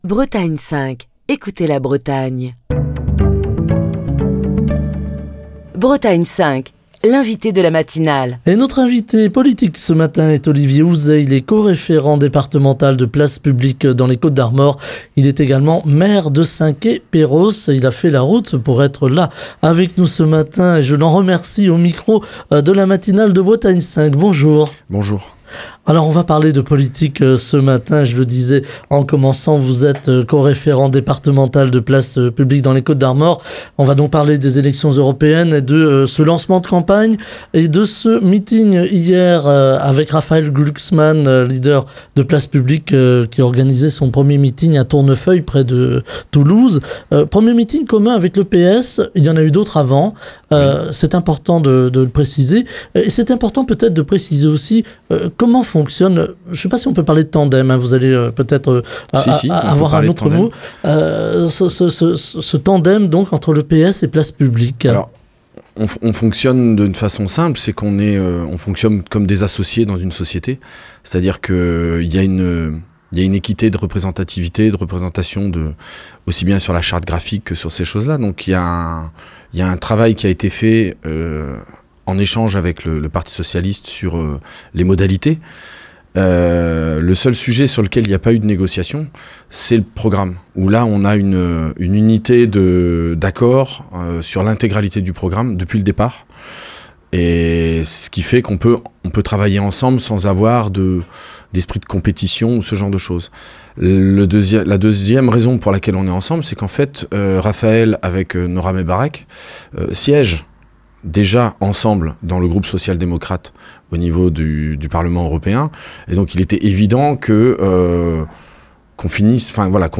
On parle des élections européennes du 9 juin ce matin avec Olivier Houzet, co-référent départemental de Place Publique dans les Côtes d'Armor, maire de Saint-Quay-Perros, qui est l'invité politique de Bretagne 5 Matin. Il revient sur la nécessité de revoir le fonctionnement de l'UE pour construire une Europe plus politique avec des objectifs précis, comme la création d'une défense européenne, la mise en place d'une politique sociale, ou encore la construction d'une Europe fédérale.